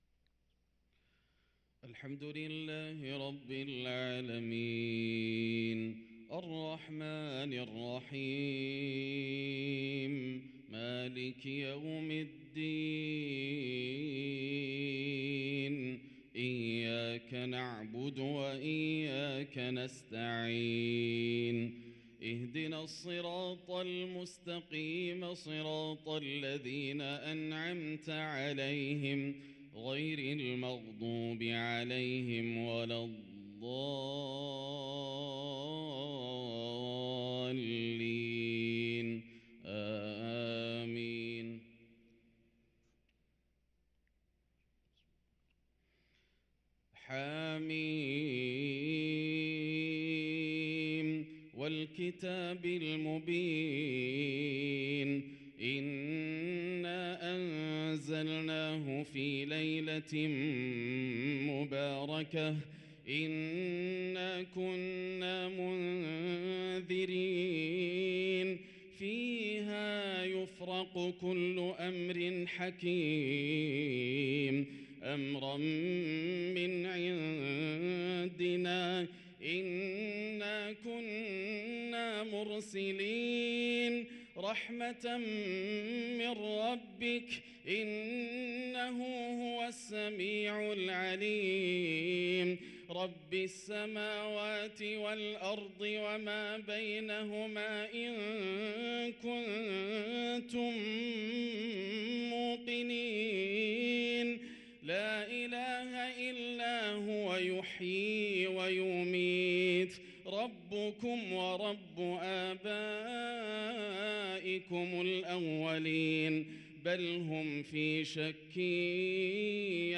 صلاة الفجر للقارئ ياسر الدوسري 21 جمادي الآخر 1444 هـ
تِلَاوَات الْحَرَمَيْن .